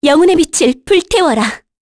Talisha-Vox_Skill3_kr.wav